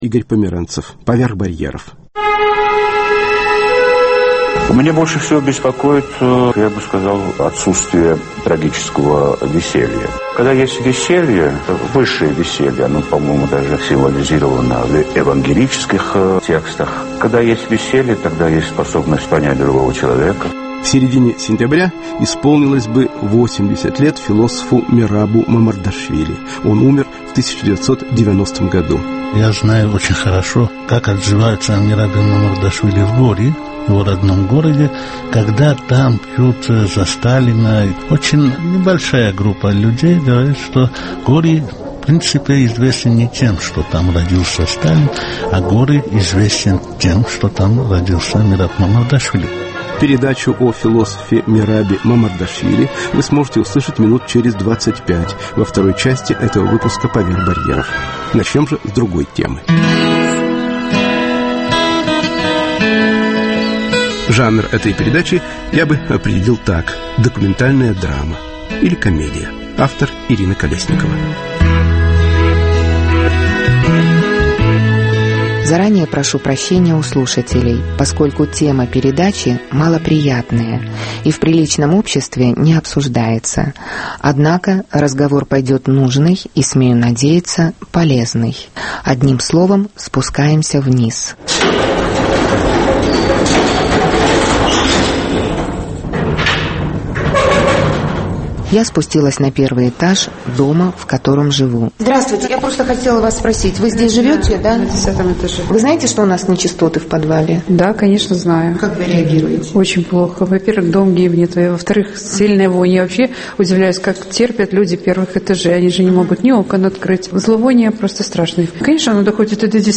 Физиологический радиоочерк.